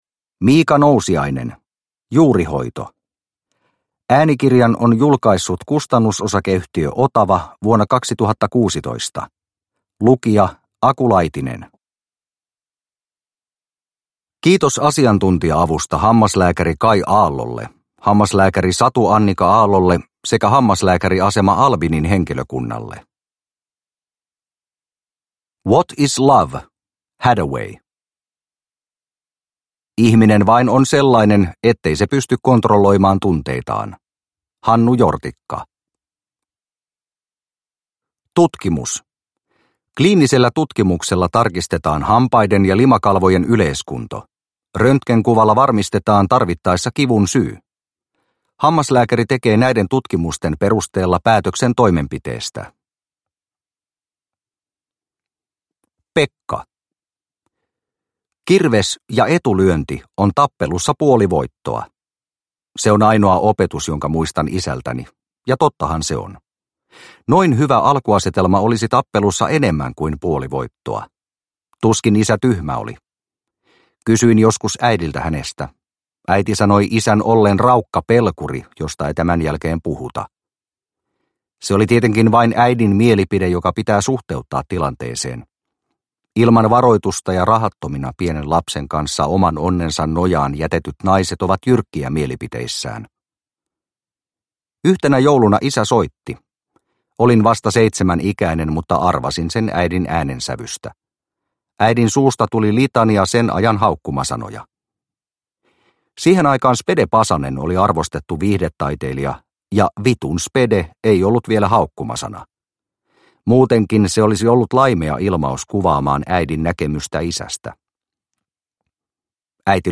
Juurihoito – Ljudbok – Laddas ner